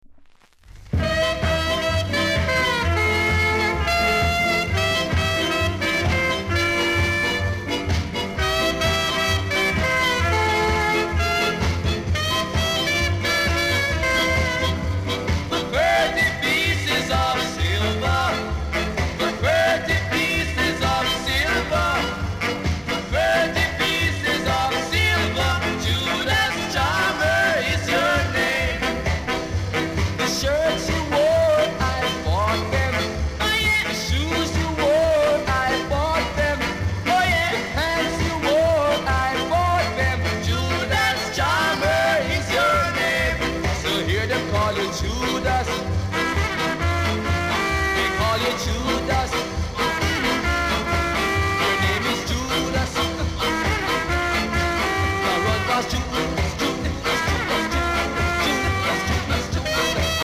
※小さなチリノイズが少しあります。
両面BIG SKA!!